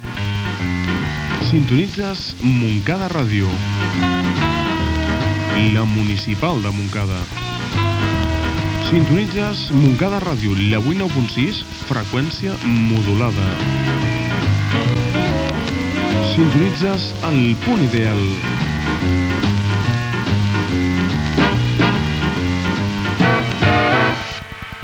b92f1ffe47889f64ccda5f4ad1ec7c92d38c9860.mp3 Títol Montcada Ràdio Emissora Montcada Ràdio Titularitat Pública municipal Descripció Identificació de l'emissora.